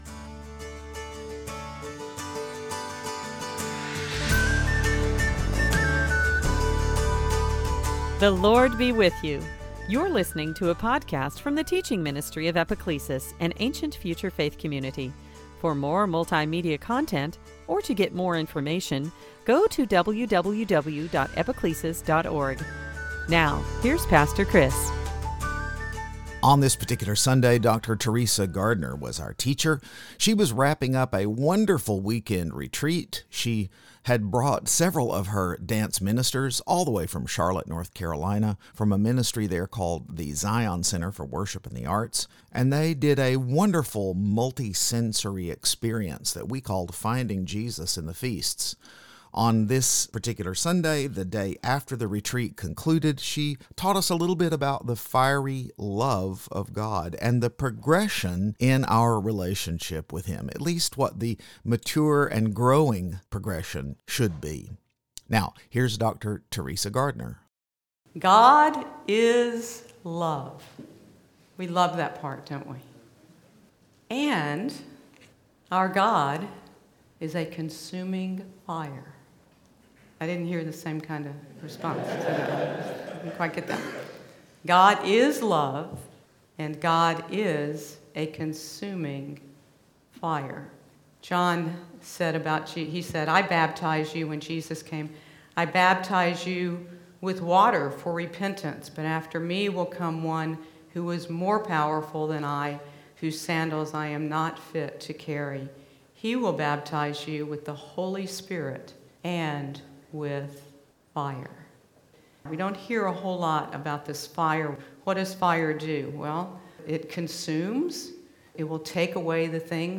We quite rightly embrace and find comfort in the truth that God is love, but should we rejoice that God is also a fire? Join us for this encouraging teaching.